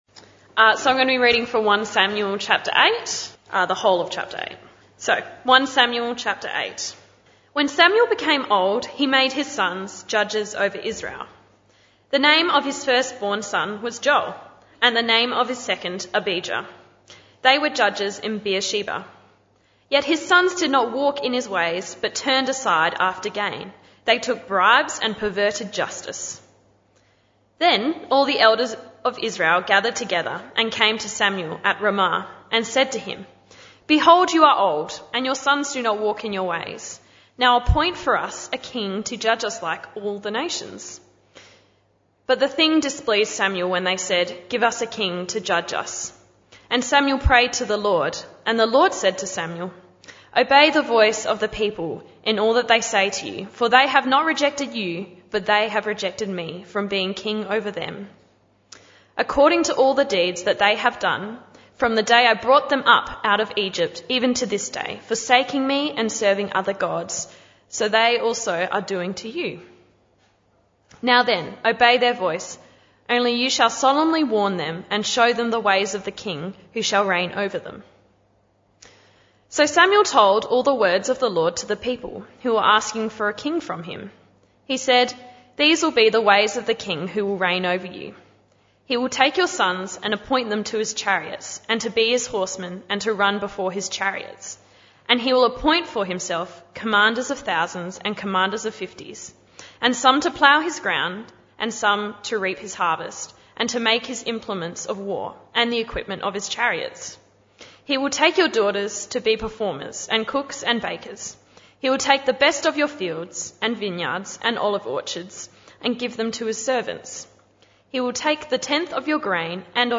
This talk was part of the PM Service series entitled The Rise of the Fallen Kings (Talk 4 of 13). Text: 1 Samuel 8.